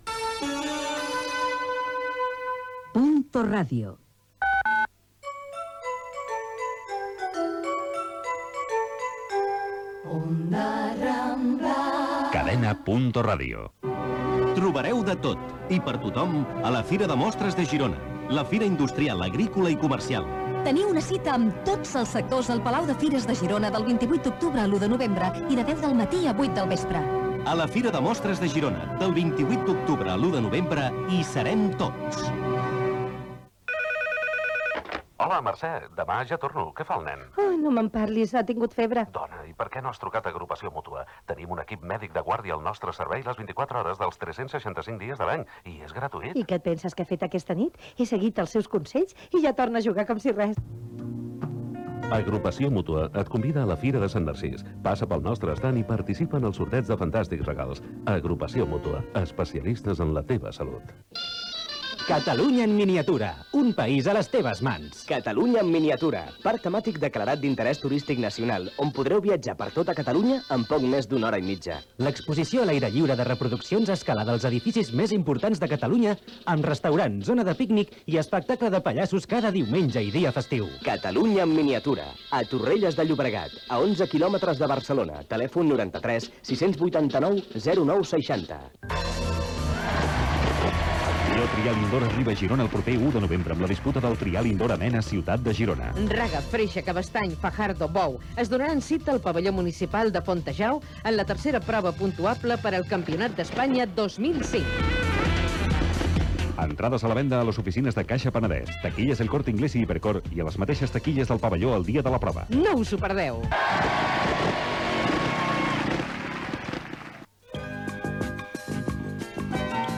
Indicatiu de la cadena i de l'emissora, publicitat, anunci del programa especial de "Protagonistas" a Girona, indicatiu, hora
FM